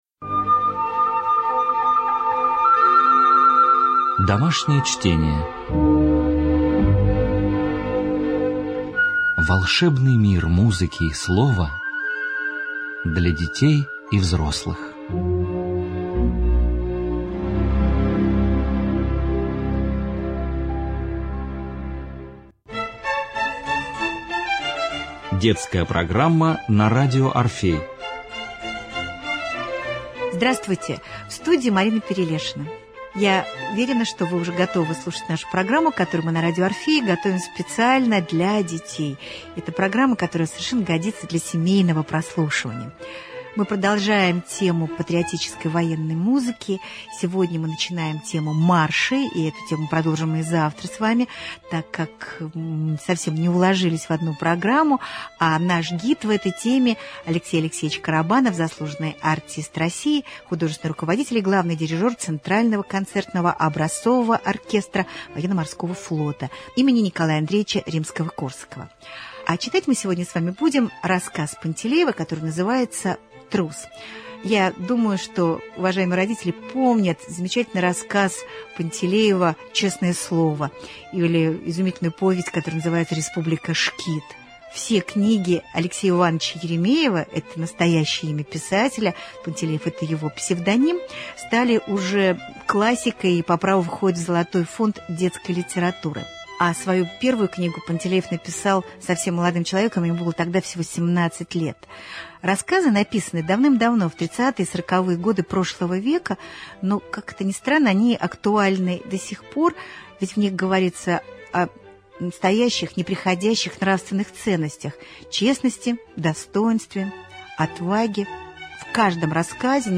Цикл бесед о патриотической и военной музыке.